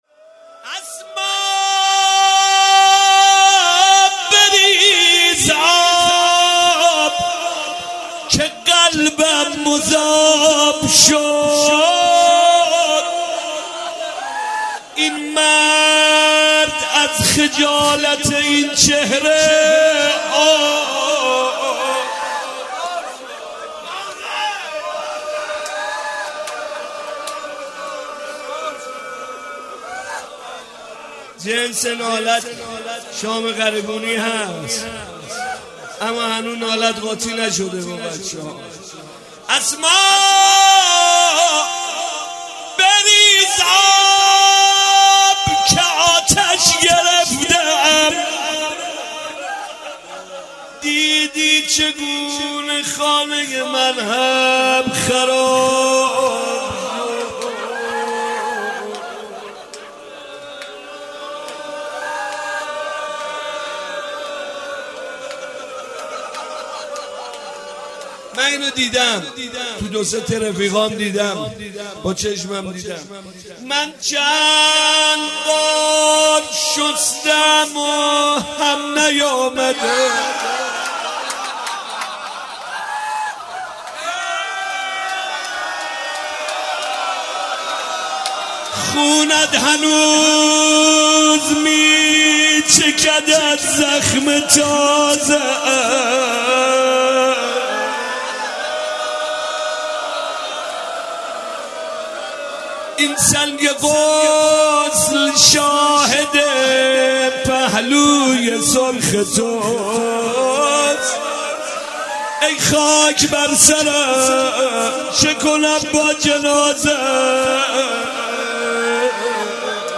مداحی و نوحه
دیدی چگونه خانه من هم خراب شد» [شب پنچ فاطمیه اول] [روضه]